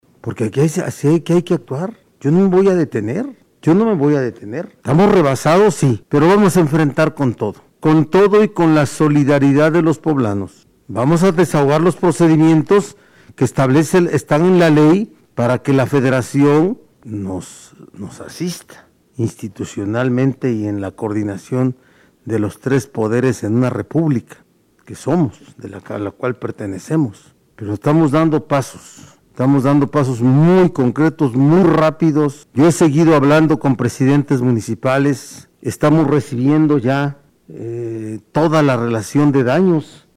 En conferencia de prensa, el gobernador señaló que ante este tipo de fenómenos naturales se debe responder con rapidez, por lo que este día se llevarán láminas para comenzar la reparación de viviendas afectadas.